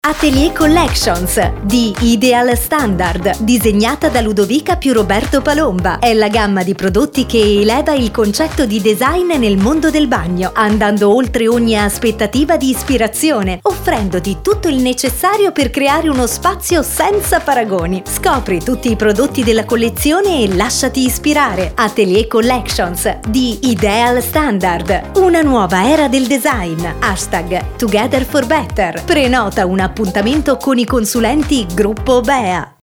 IdealStandardAteliercollectionSPOT.mp3